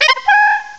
cry_not_chatot.aif